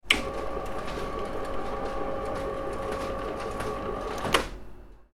Rolling Shutter Mechanism Turning On And Off Sound Effect
You hear a button click that starts the rolling shutter or a similar mechanism. The motor runs smoothly as the shutter moves up or down. Finally, a clear click signals when the mechanism stops. Mechanical sounds.
Rolling-shutter-mechanism-turning-on-and-off-sound-effect.mp3